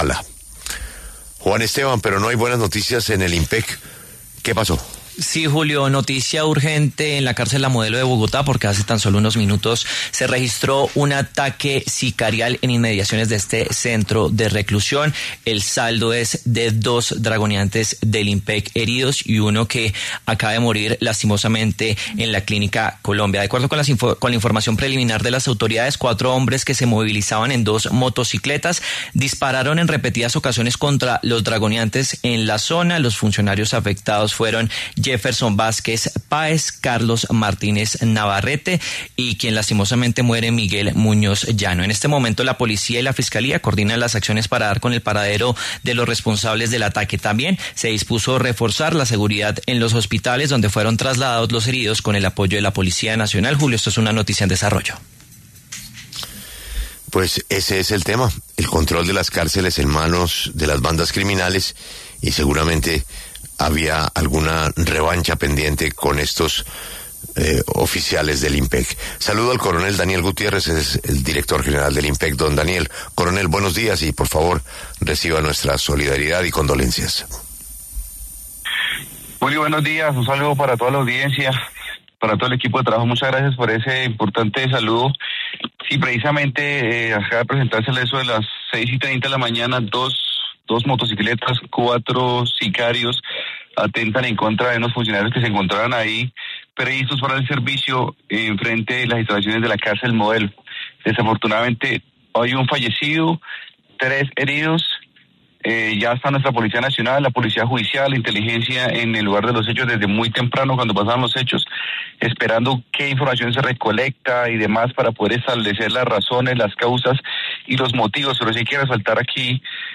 El coronel Daniel Gutiérrez, director del Inpec, habló en La W sobre el ataque sicarial contra funcionarios del la entidad en la cárcel La Modelo en Bogotá.